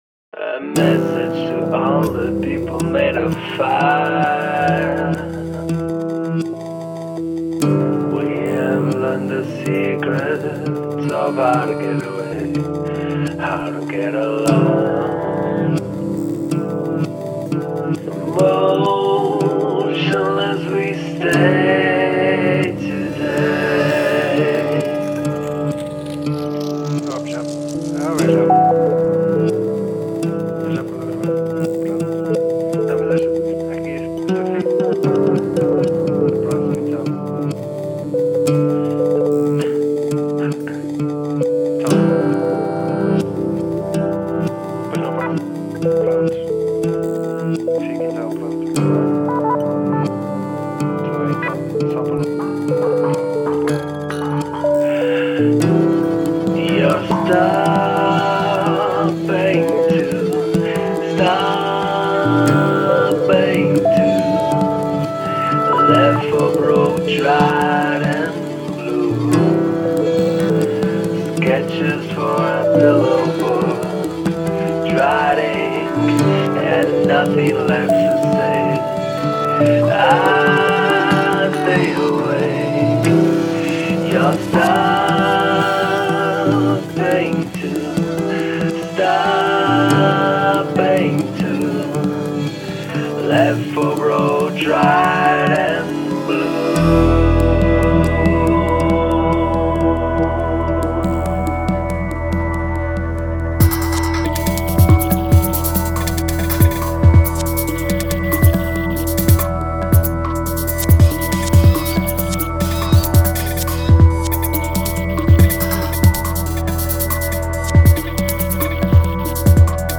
Gloriously unusual IDM (or "poptronica")
Im Reagenzglas: Candid-IDM-Pop with Glitches.